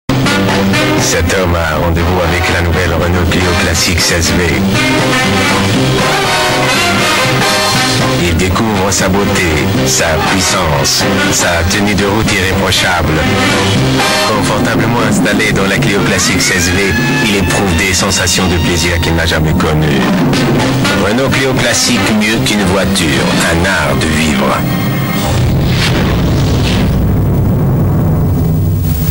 Bandes-son